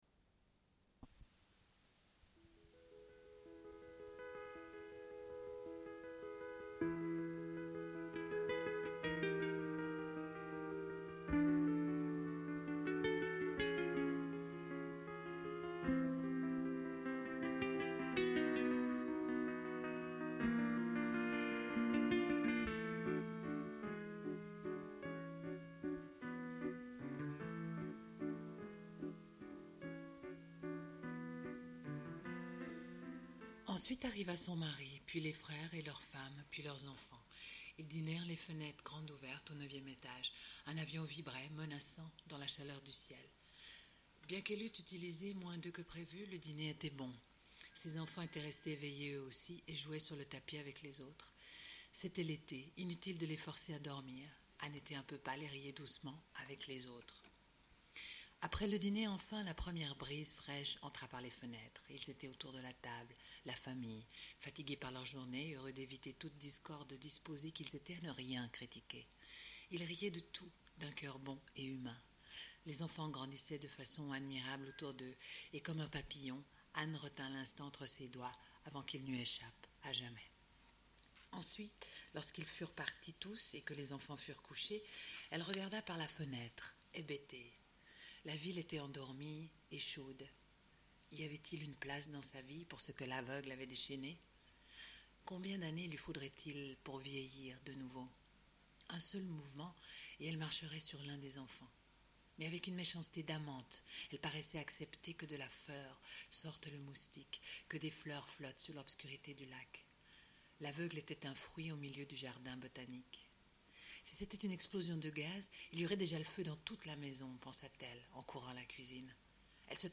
Suite de l'émission : au sujet de Clarice Lispector, écrivaine brésilienne. Lecture d'extraits de ses nouvelles Amour, Miss Algrane et Bruit de Pas.
Une cassette audio, face B